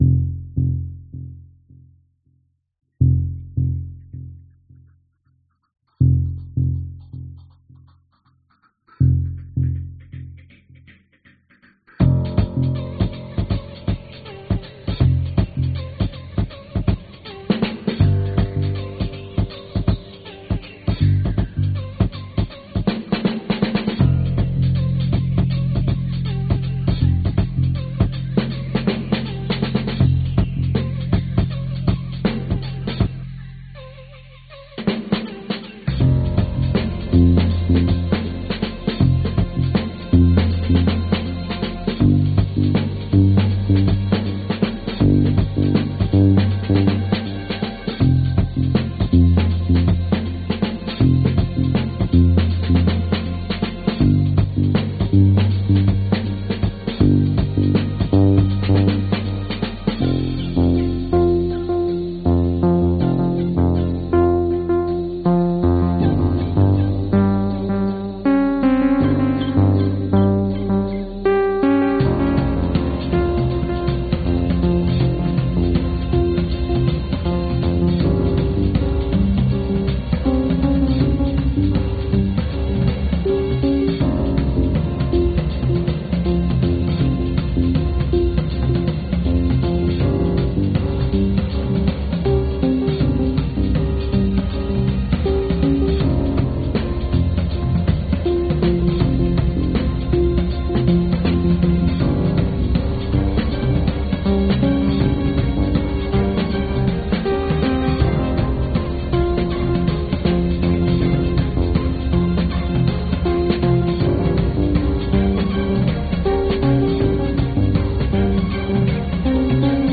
泥火山萨尔顿海
标签： 戴维斯 - Schrimpf 火山 泥浆罐 狮鹫 热弹簧 二氧化碳 狮鹫 索尔顿海 气泡 沸腾 流体 喷发 水热 气体 现场记录 气泡 加利福尼亚州 字段 熔岩 泥浆 间歇泉 汩汩 温泉 鼓泡 渗出 火山
声道立体声